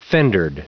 Prononciation du mot fendered en anglais (fichier audio)